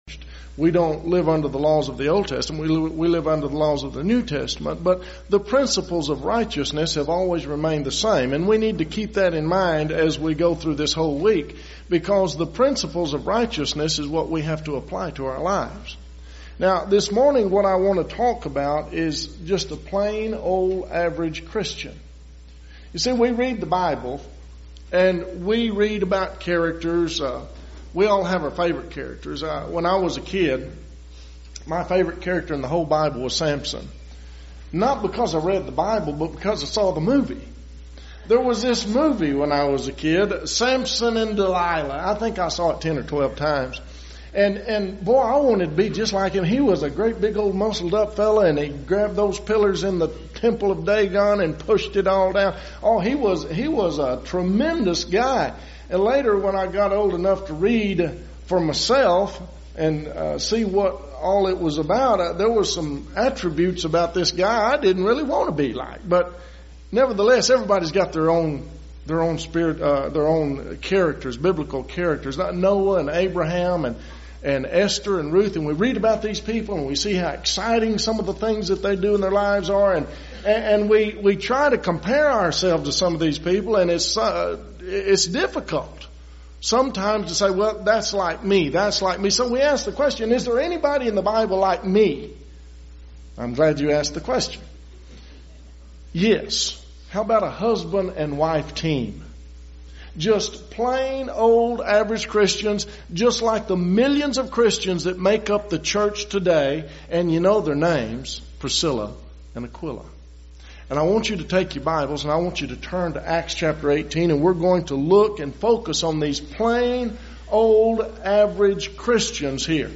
Spring Gospel Meeting
lecture